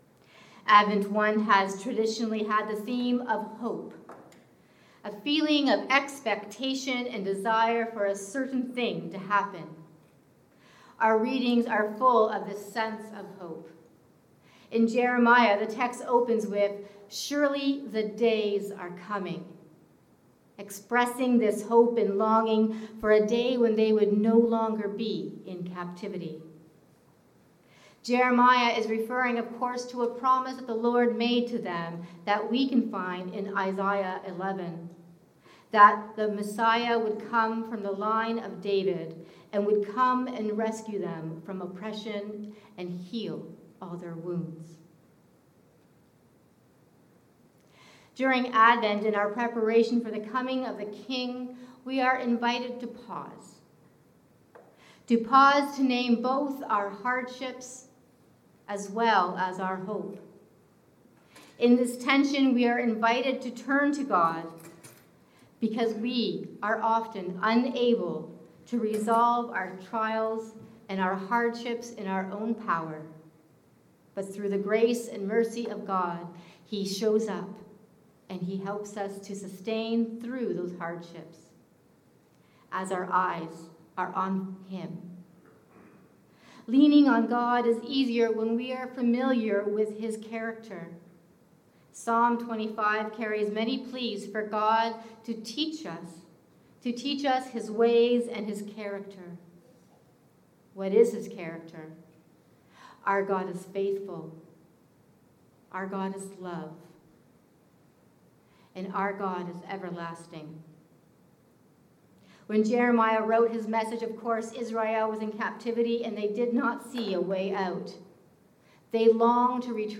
A sermon for the 1st Sunday of Advent – St. Helen's Anglican Church
Advent Hope. A sermon for the 1st Sunday of Advent